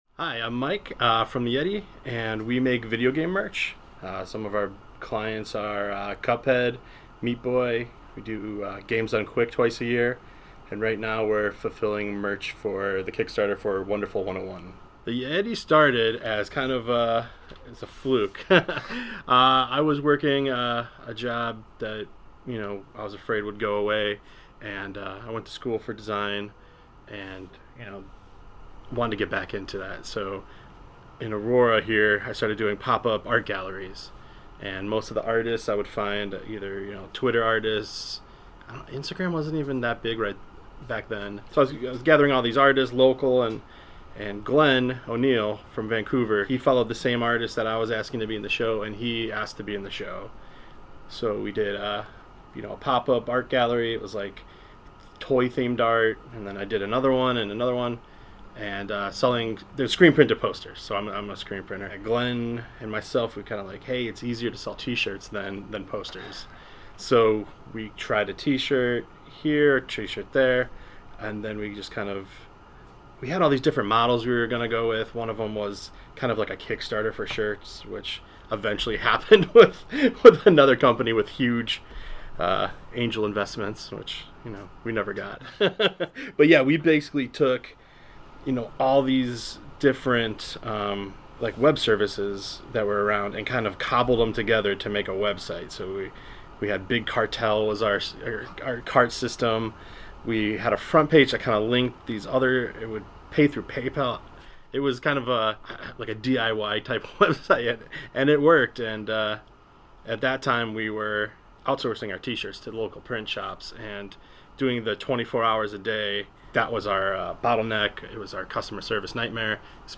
for a conversation about their unique business.